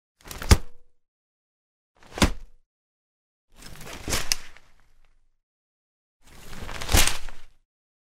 Звуки зонтика
1. Звук раскрывающегося зонта n2. Как звучит открытие зонта n3. Шум раскрытого зонта n4. Услышать звук зонта n5. Звук зонта при открытии